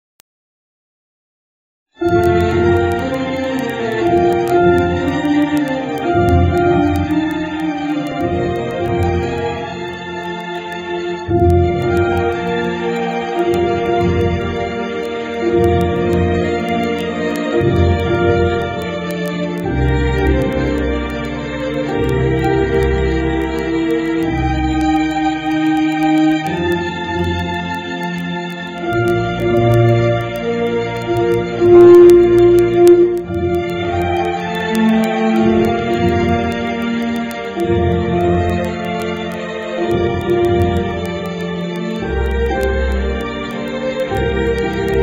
NOTE: Background Tracks 11 Thru 22